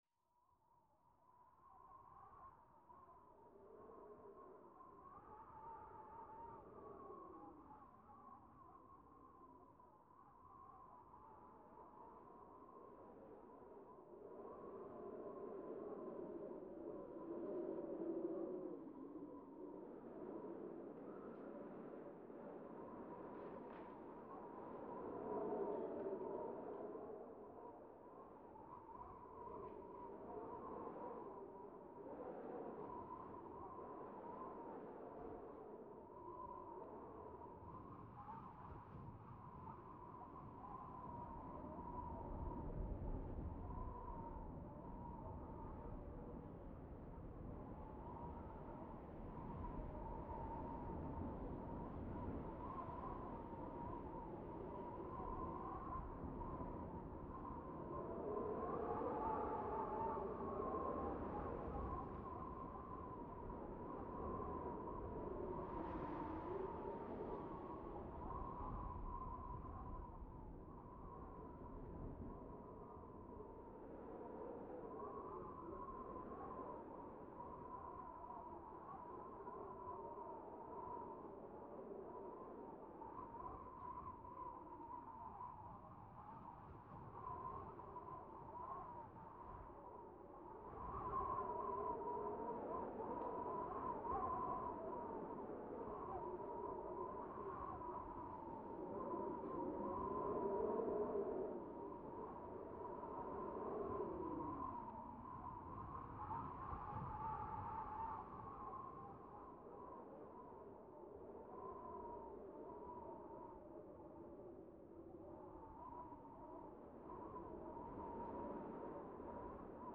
Wind 10 LR Cold Whistley.wav